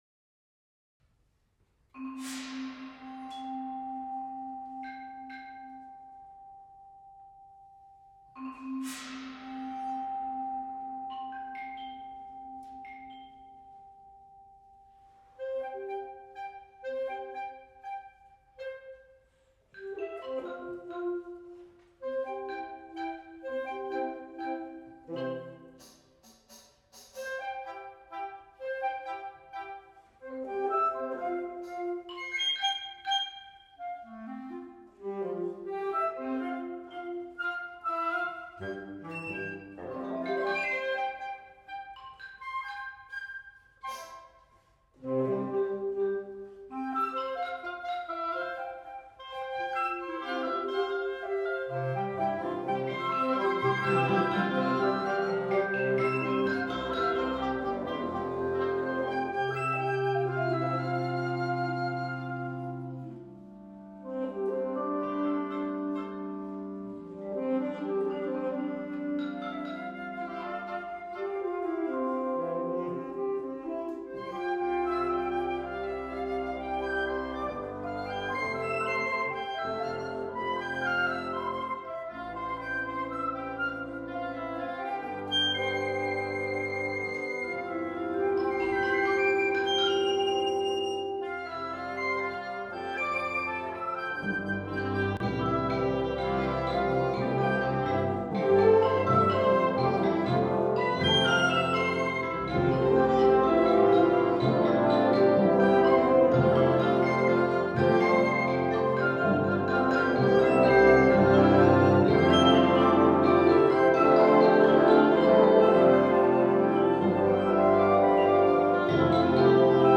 First Flight performed by the Chicago College of Performing Arts Wind Symphony in February 2023.